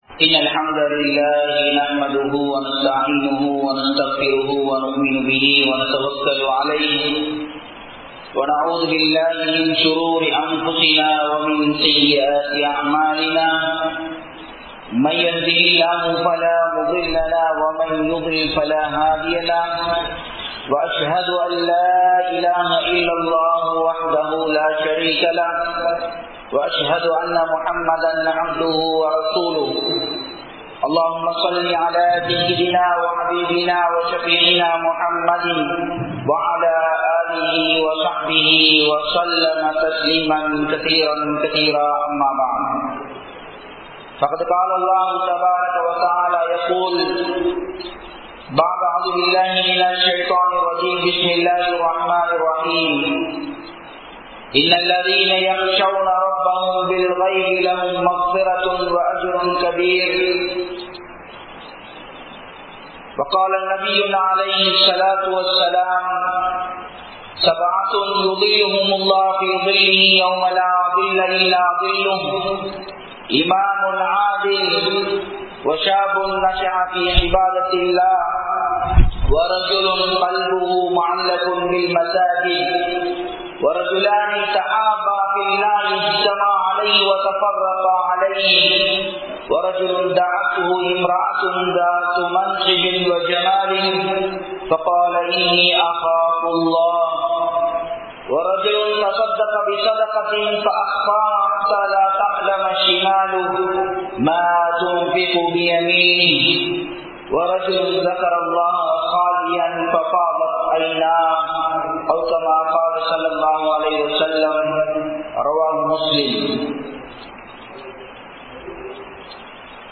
Samooka Valaithalankalinaal Etpadum Theenkukal(சமூக வலைதலங்களினால் ஏற்படும் தீங்குகள்) | Audio Bayans | All Ceylon Muslim Youth Community | Addalaichenai